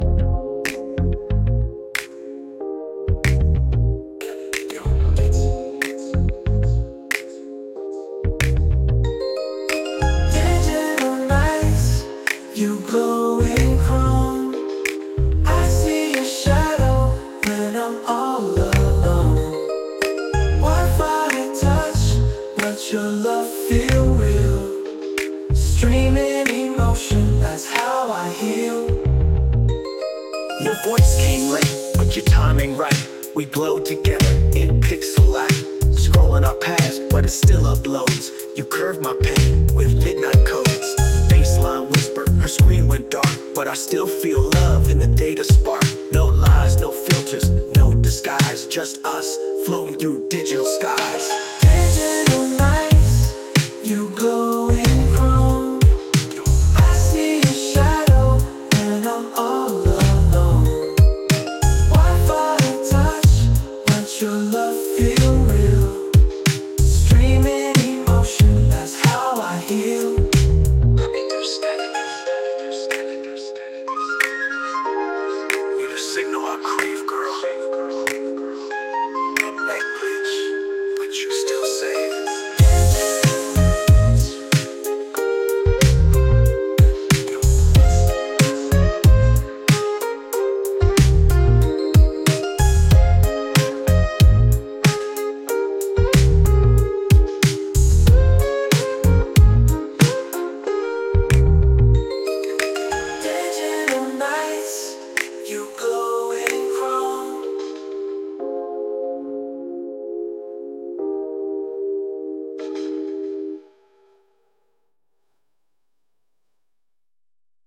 slow-burning trap-soul masterpiece
• 80s Electro-Pop Funk
• R&B Glow Synths
• Dreamwave Vocal Layers
Float through a hypnotic soundscape